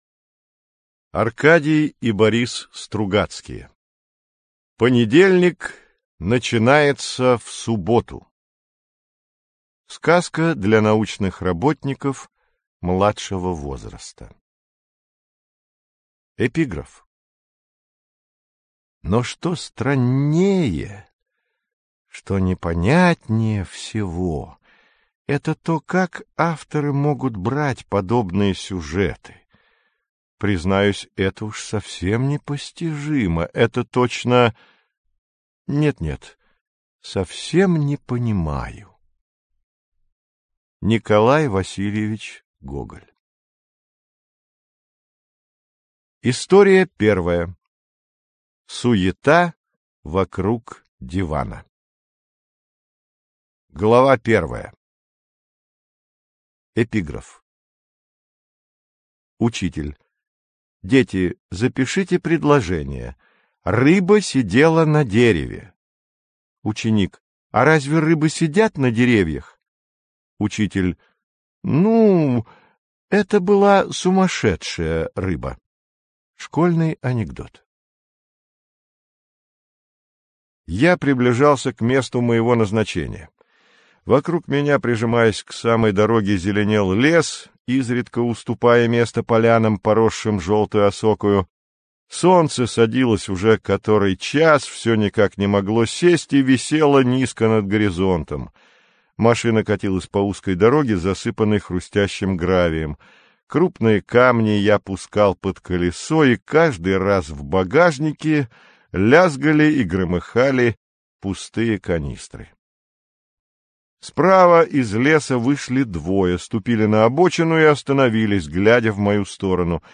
Аудиокнига Понедельник начинается в субботу - купить, скачать и слушать онлайн | КнигоПоиск